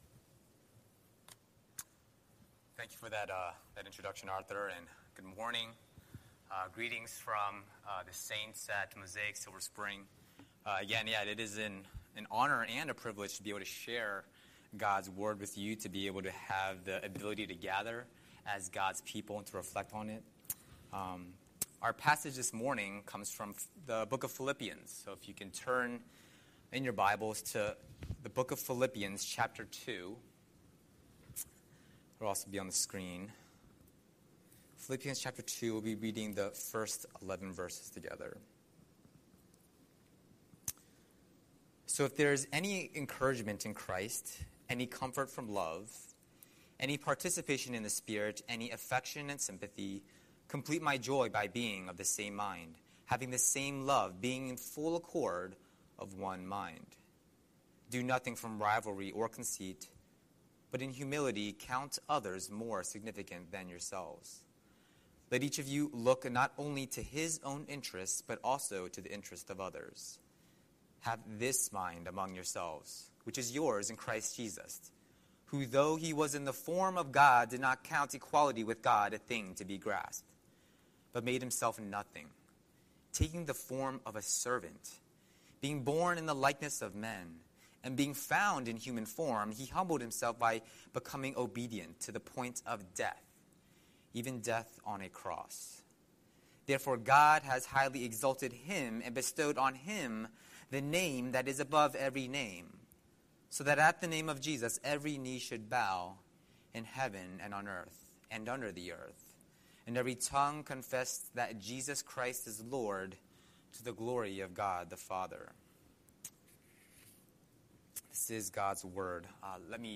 Scripture: Philippians 2:1-11 Series: Sunday Sermon